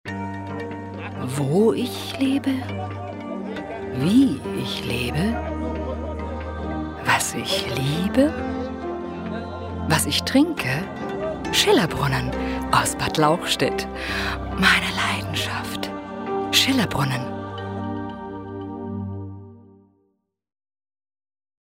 deutsche Sprecherin für Werbung, Dokumentationen, Voice Over, TV, Radio uvm.
Kein Dialekt
Sprechprobe: eLearning (Muttersprache):
female german voice over artist